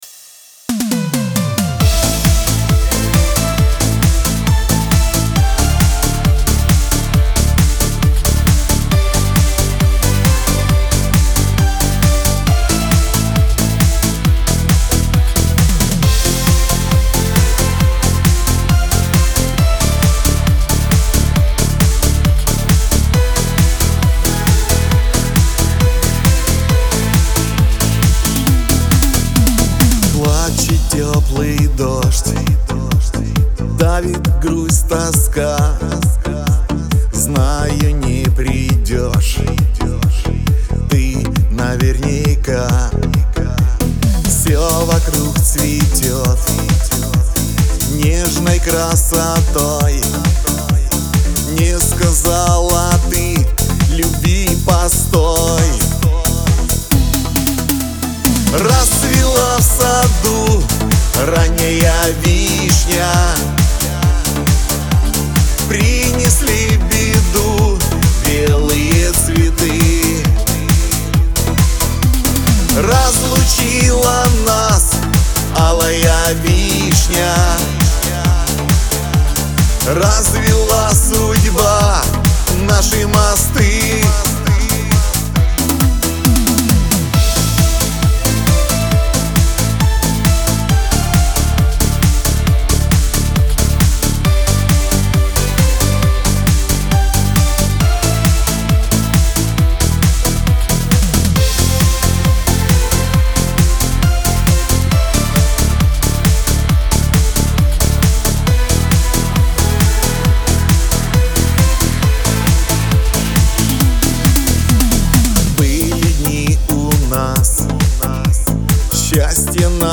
Лирика
эстрада , pop
грусть